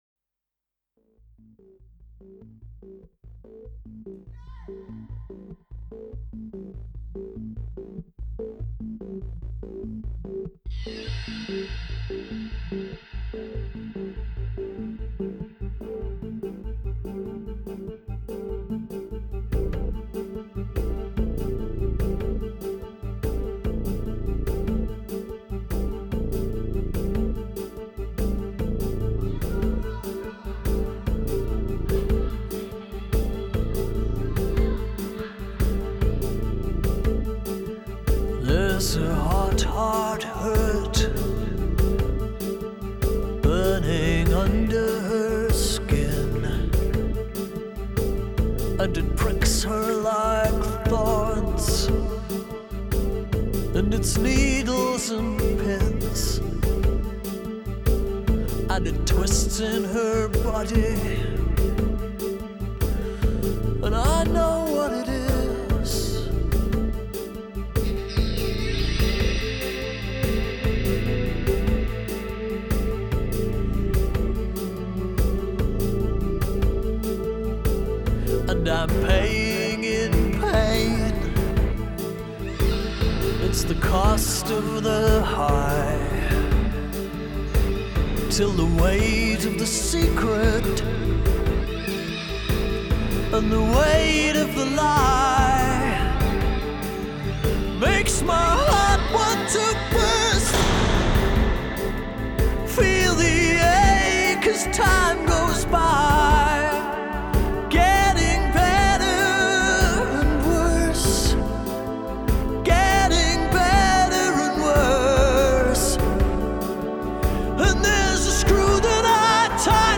Genre : Progressive Rock